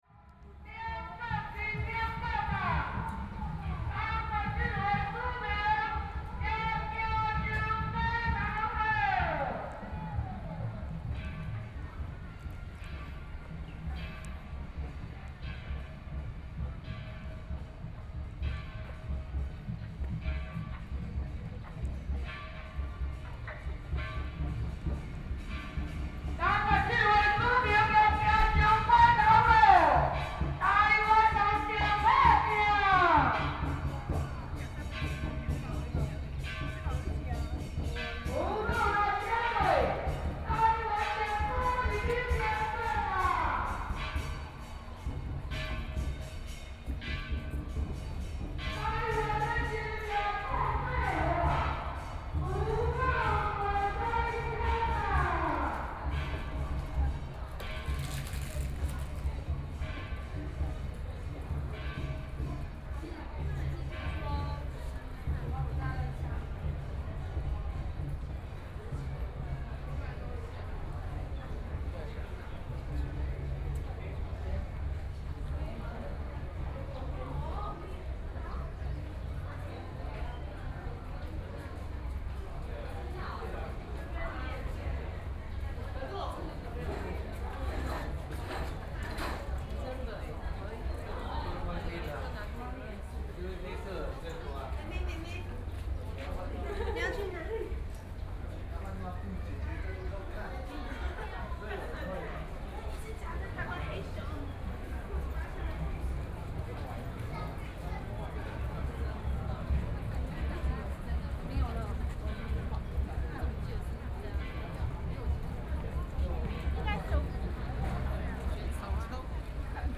Xinyi Rd.,Taipei City - Walking through the site in protest